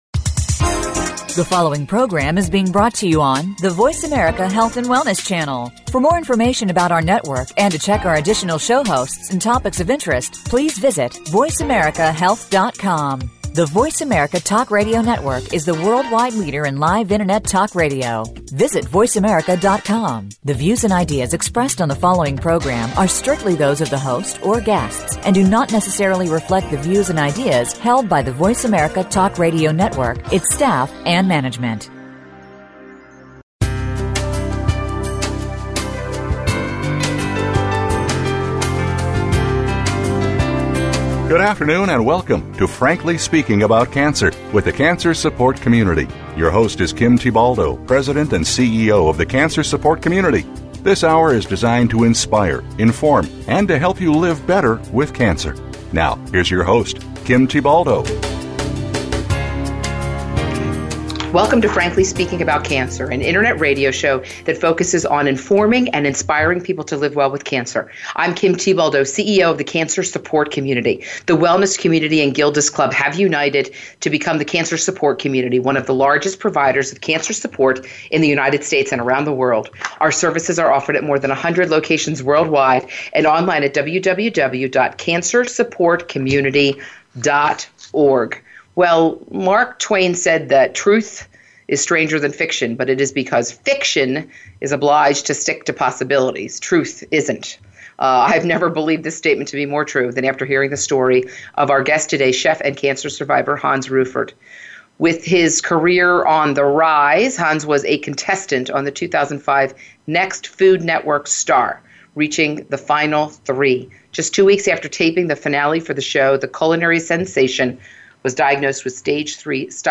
A Chef with No Stomach- A conversation